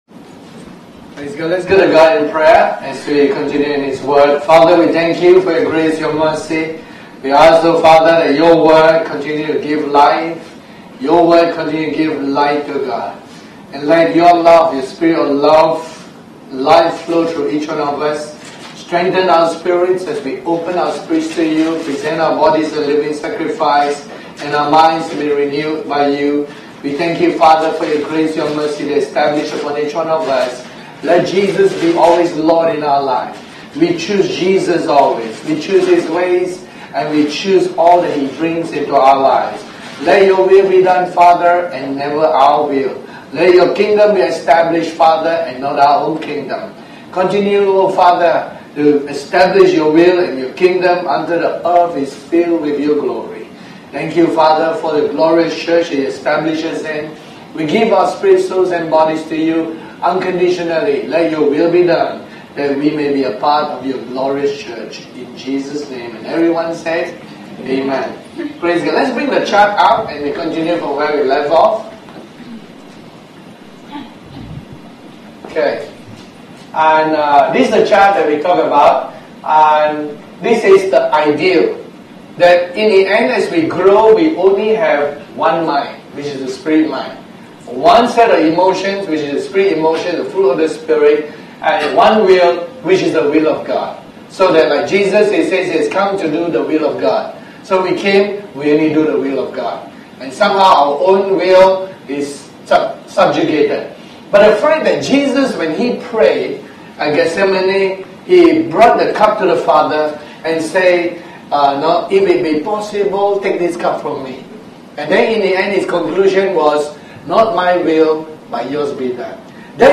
Series: The Life of God Tagged with Sunday Service